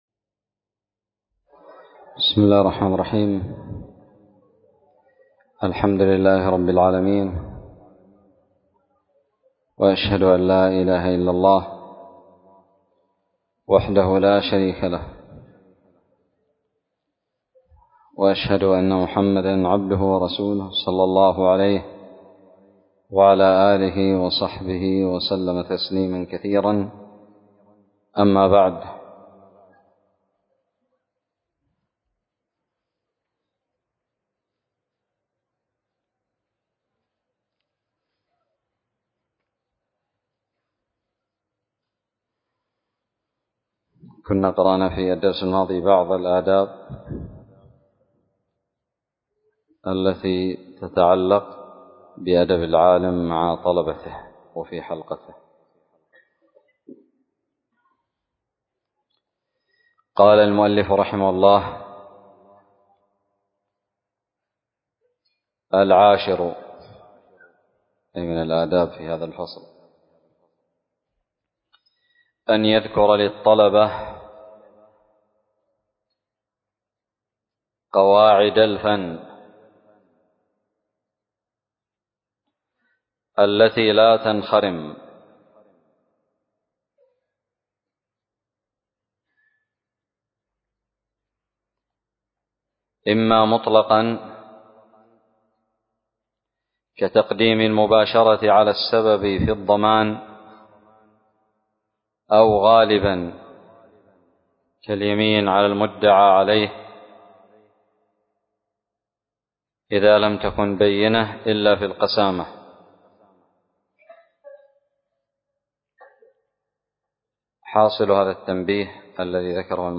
الدرس الثامن عشر من شرح كتاب تذكرة السامع والمتكلم 1444هـ
ألقيت بدار الحديث السلفية للعلوم الشرعية بالضالع